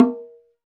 PRC BONGOL00.wav